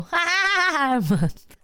677 Goofy Ahh Laugh Sound Button - Free Download & Play